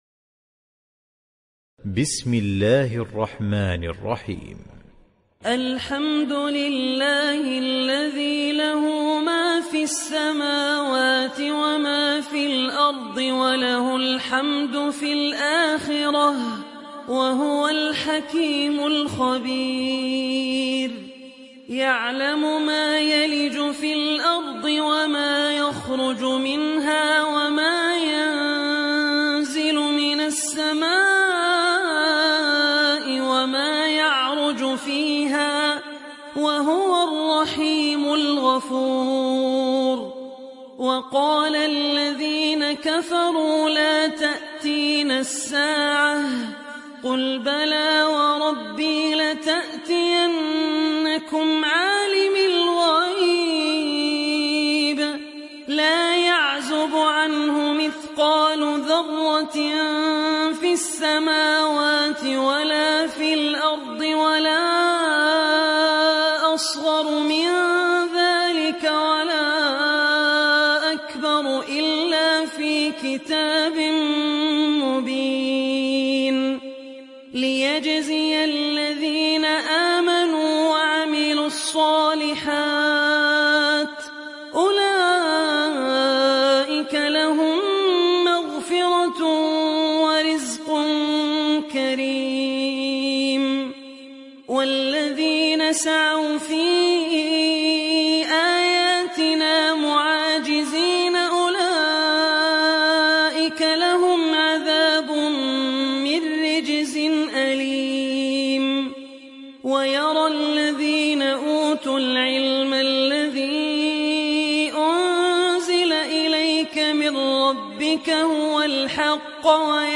সূরা সাবা ডাউনলোড mp3 Abdul Rahman Al Ossi উপন্যাস Hafs থেকে Asim, ডাউনলোড করুন এবং কুরআন শুনুন mp3 সম্পূর্ণ সরাসরি লিঙ্ক